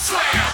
SLAM.wav